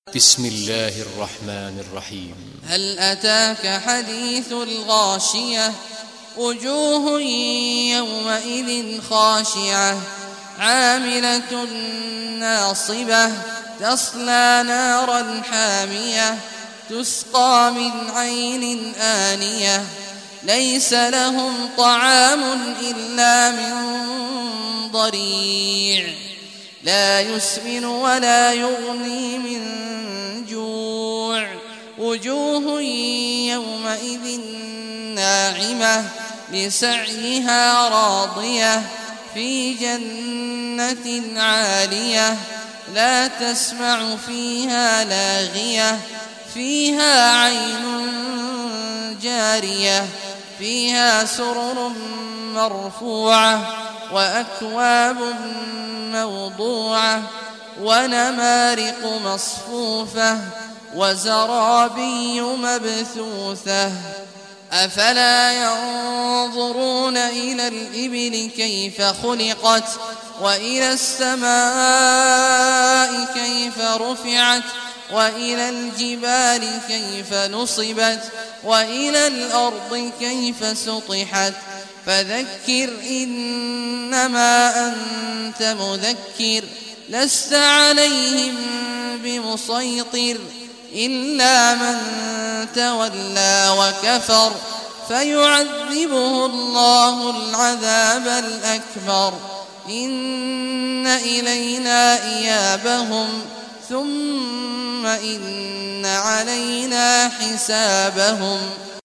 Surah Ghashiyah Recitation by Sheikh Awad Juhany
Surah Al-Ghashiyah, listen or play online mp3 tilawat / recitation in Arabic in the beautiful voice of Sheikh Abdullah Awad al Juhany.